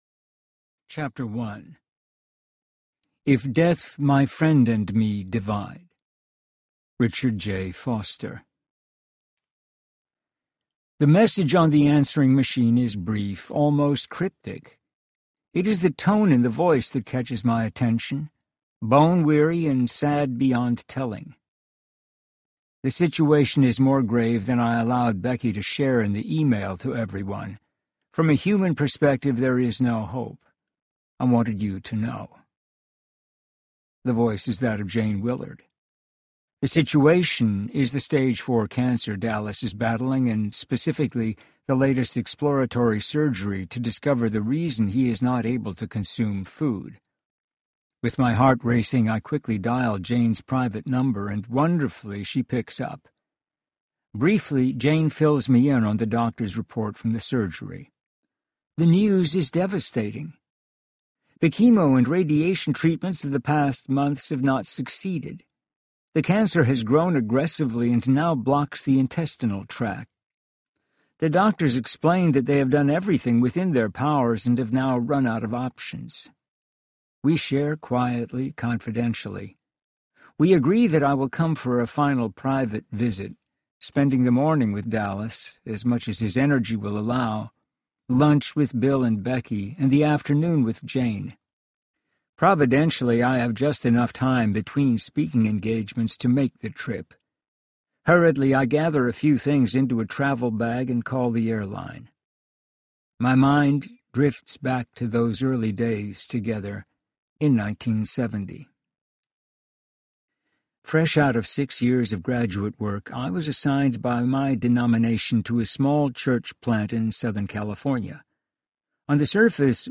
Eternal Living Audiobook
8.25 Hrs. – Unabridged